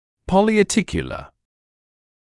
[ˌpɔlɪɑː’tɪkjələ][ˌполиаː’тикйэлэ]полиартикулярный